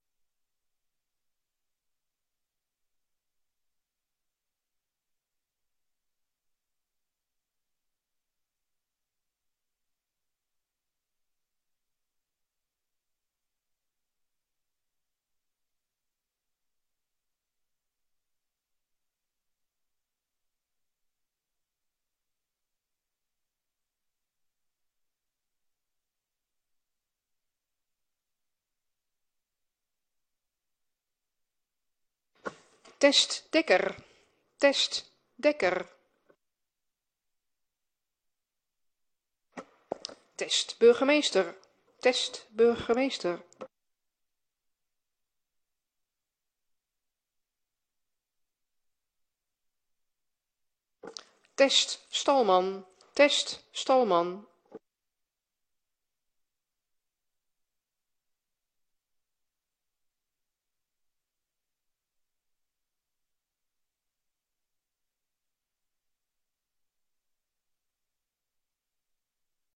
Audiotest door griffie 04 februari 2025 16:30:00, Gemeente Woudenberg
Locatie: Raadzaal